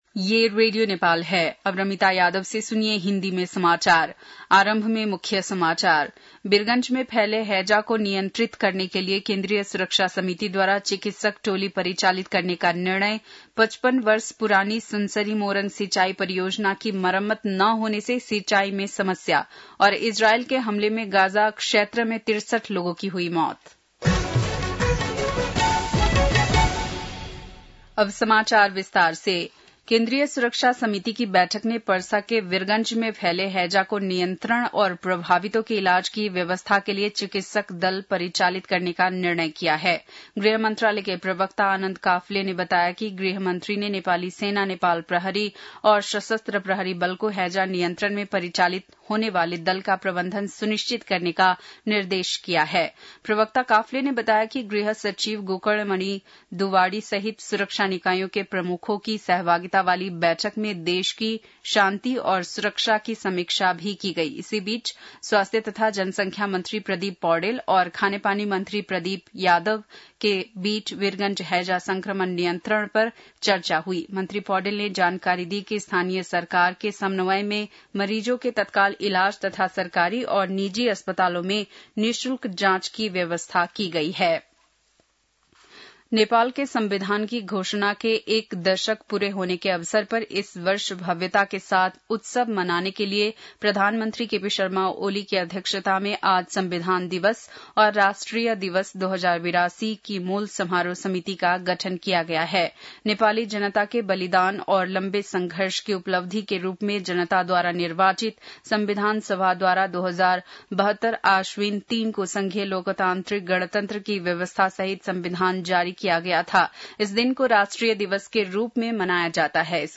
बेलुकी १० बजेको हिन्दी समाचार : ८ भदौ , २०८२
10-pm-hindi-news-5-8.mp3